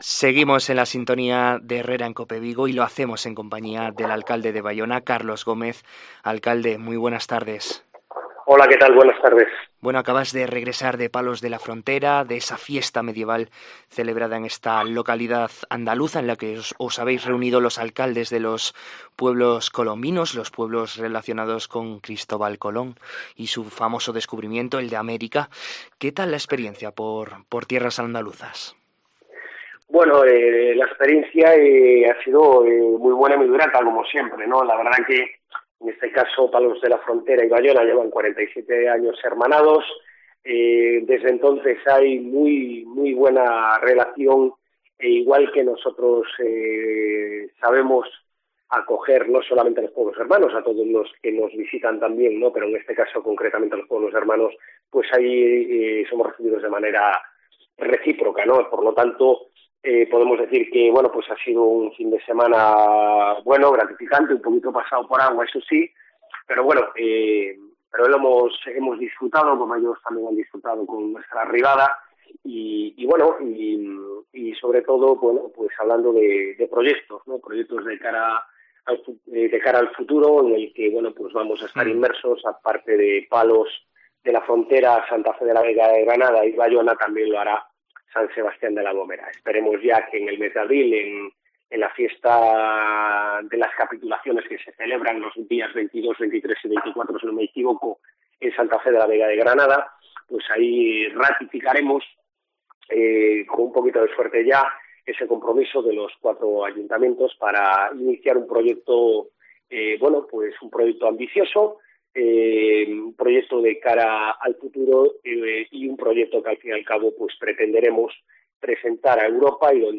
Entrevista a Carlos Gómez, alcalde de Baiona